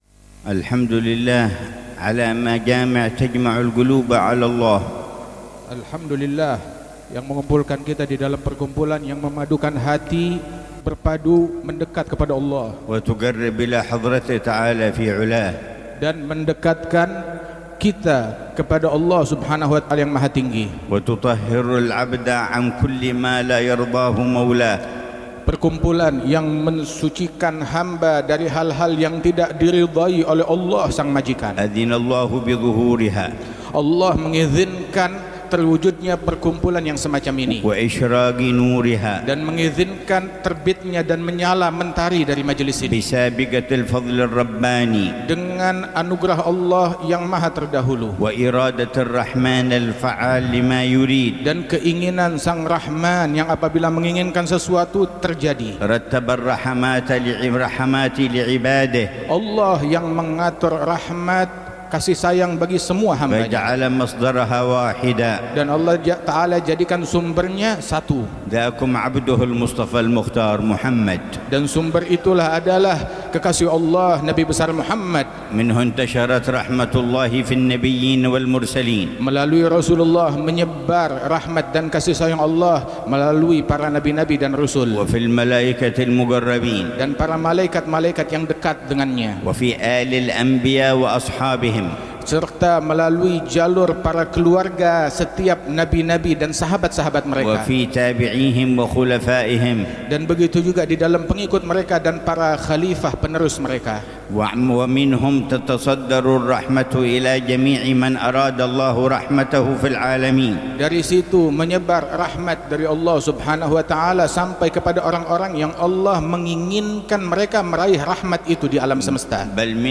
محاضرة العلامة الحبيب عمر بن حفيظ في مسجد الرياض، بمدينة صولو، مقاطعة جاوة الوسطى، إندونيسيا، ضمن فعاليات حولية الإمام علي بن محمد الحبشي، ليلة الأحد 20 ربيع الثاني 1447هـ بعنوان: